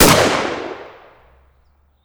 PNRoyalRifleSound.wav